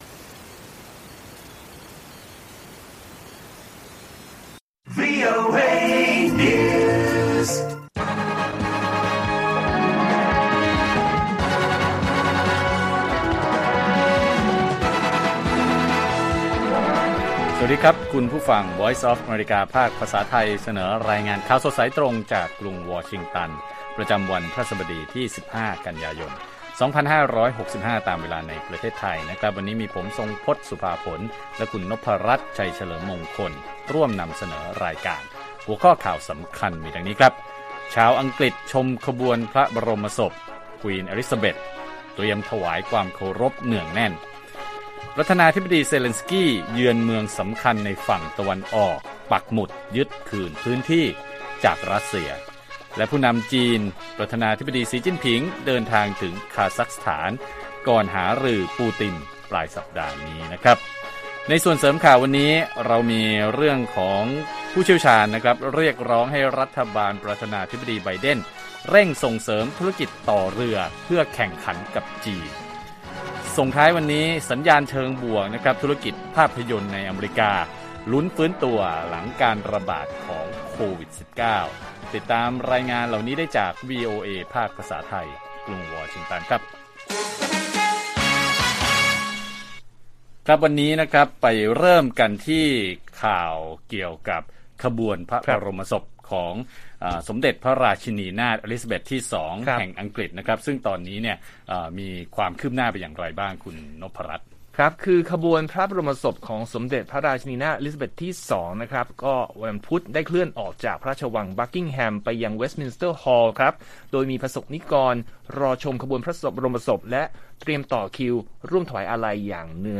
สด!